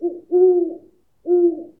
sounds_owl_hoot.ogg